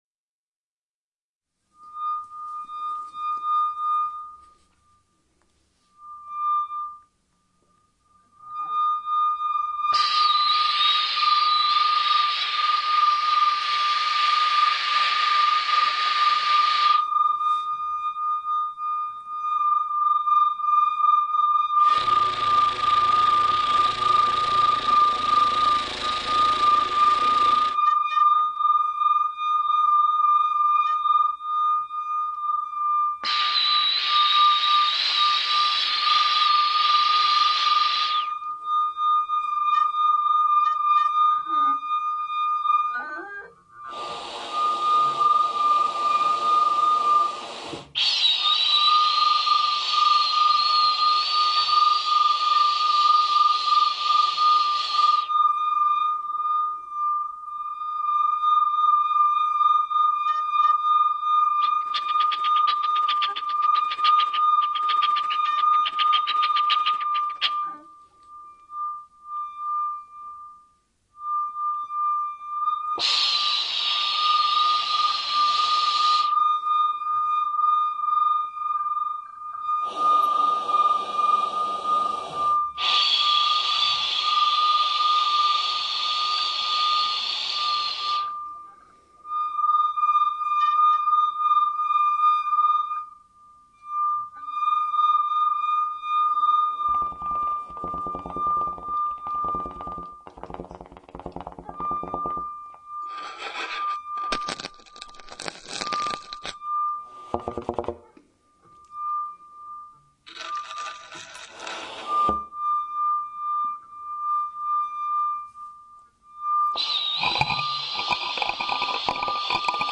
trumpet, radio, mutes, speaker, objects
Recorded 2007 in Hamburg